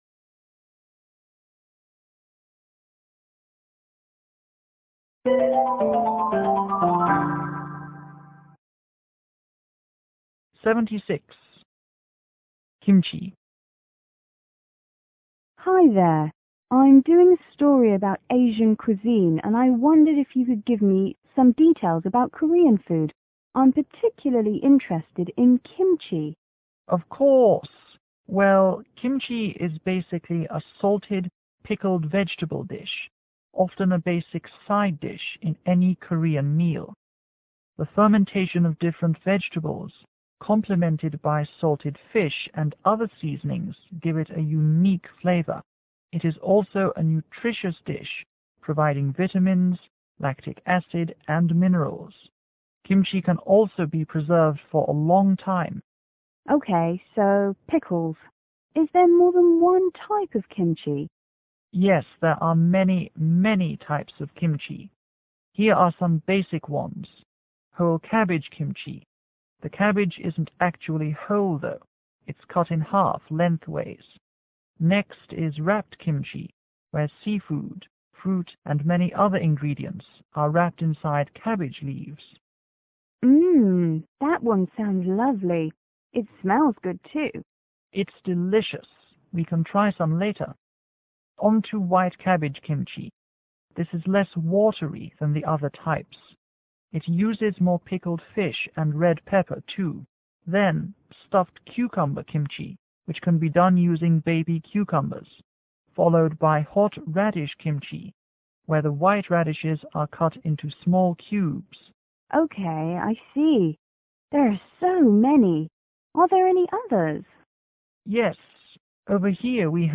R: Reporter          C: Chef